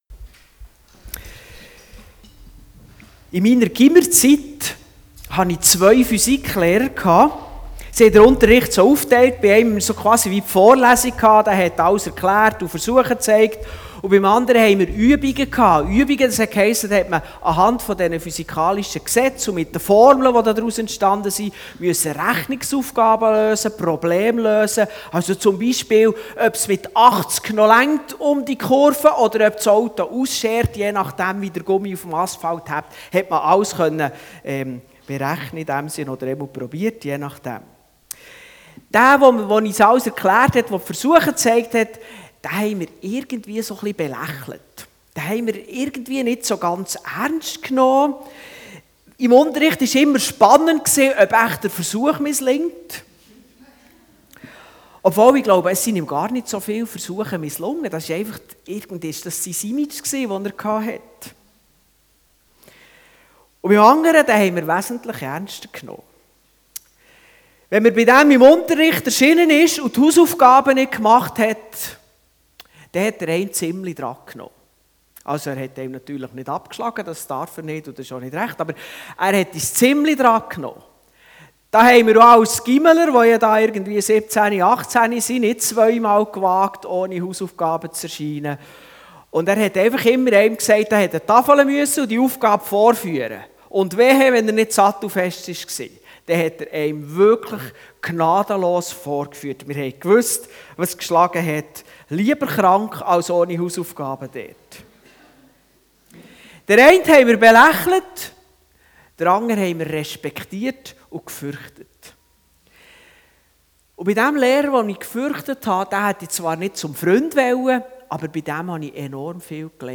Dienstart: Gottesdienst